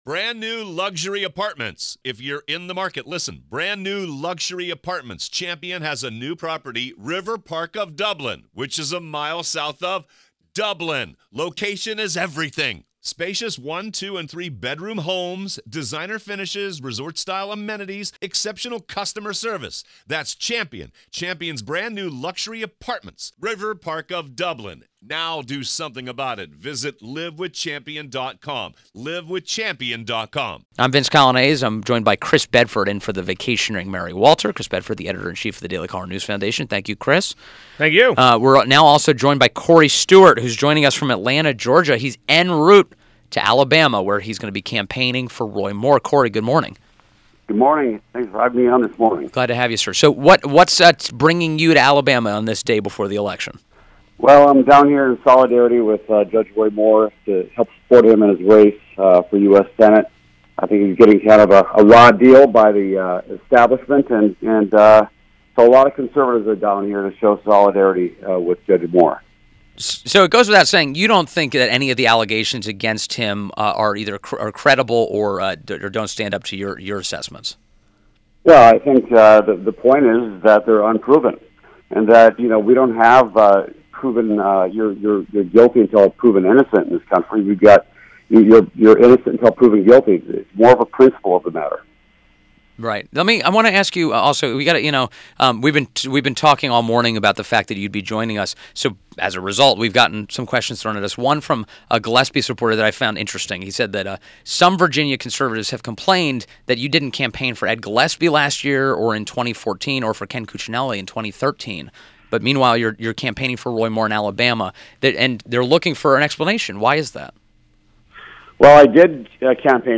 WMAL Interview - COREY STEWART - 12.11.17
INTERVIEW – COREY STEWART – is a Republican Candidate for U.S. Senate in Virginia, Former State Chairman for Donald Trump, and At-Large Board of County Supervisors Chairman of Prince William.